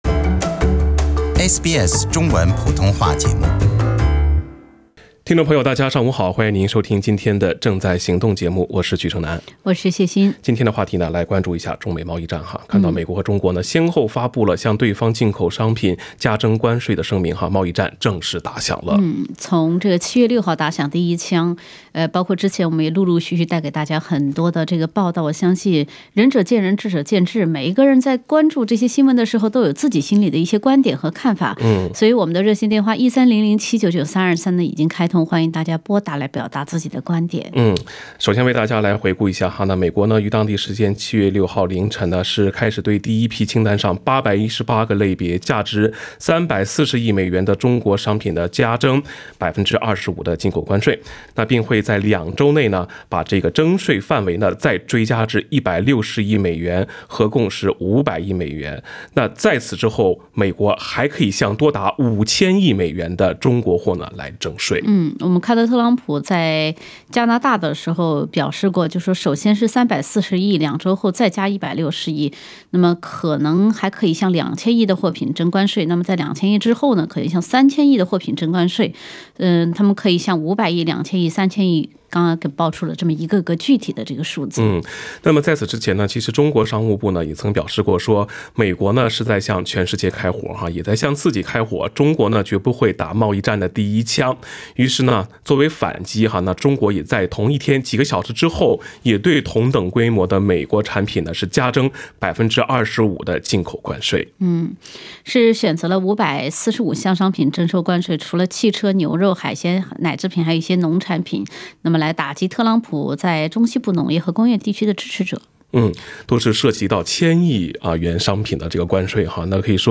时政热线节目《正在行动》逢周三上午8点半至9点播出。